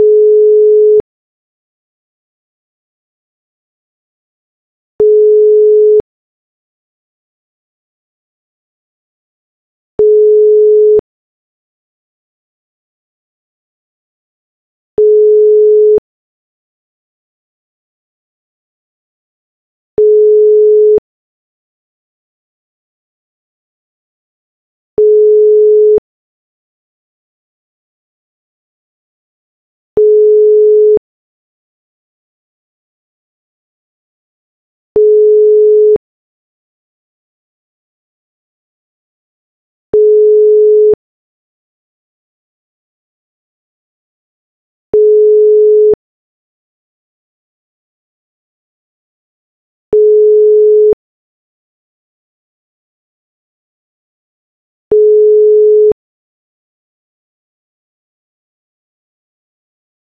ETSI 표준 기본 통화 연결음
ETSI 표준4251 on, 3-4 off
유럽 전기 통신 표준 협회(ETSI) 권고를 따르는 대부분의 유럽 국가는 425Hz 신호음을 사용한다. 일반적인 패턴은 1초 동안 신호음이 울리고 3~4초 동안 정지되는 것을 반복한다.